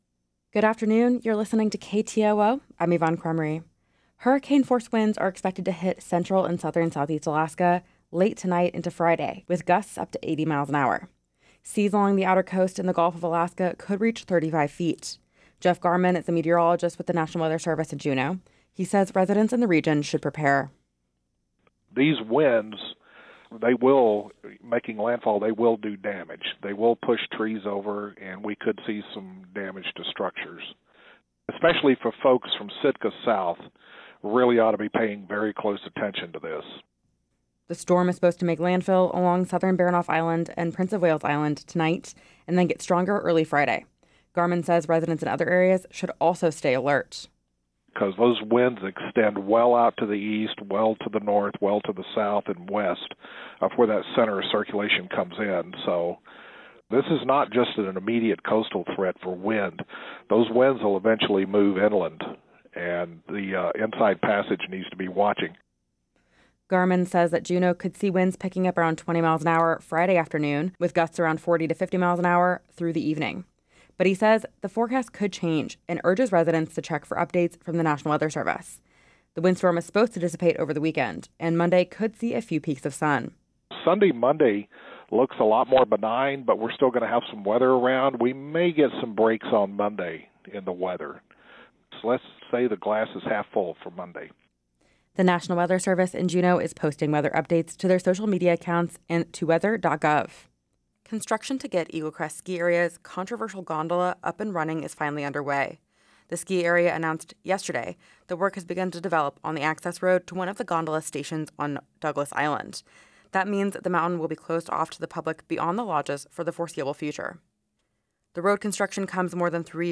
Newscast – Thursday, Sept. 25, 2025